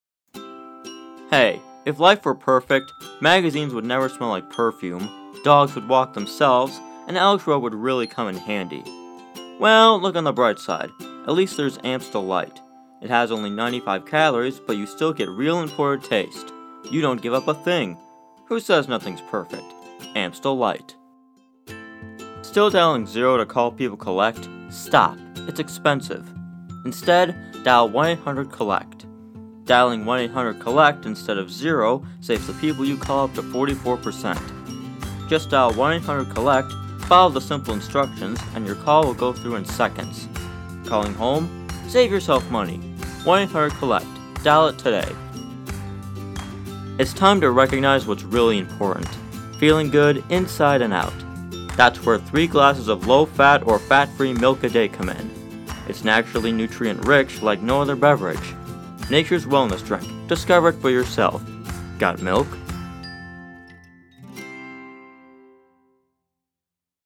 commerical-demo.mp3